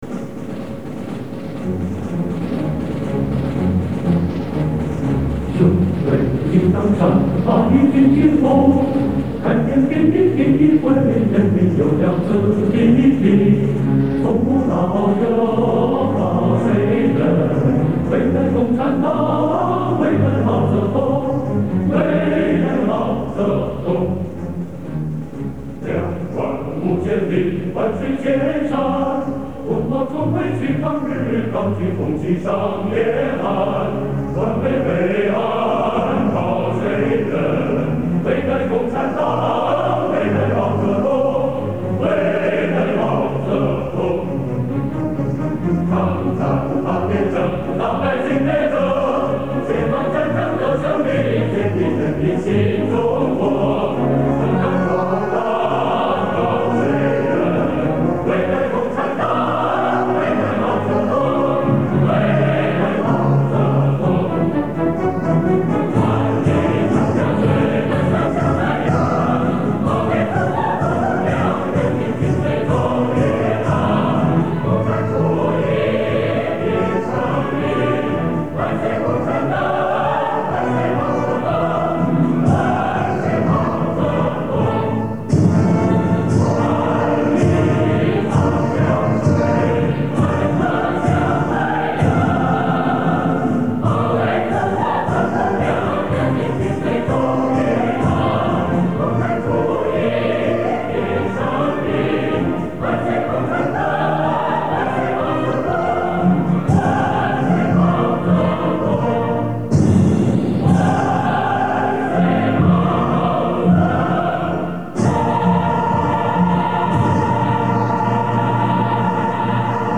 推进得有层次。